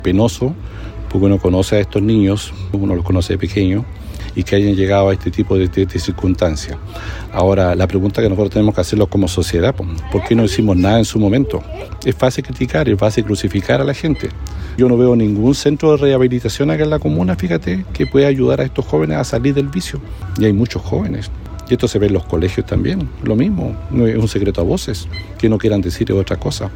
En ese sentido, otro de los vecinos asegura que en Máfil se observa mucho consumo de drogas y lamenta que en la comuna no exista ningún centro de rehabilitación.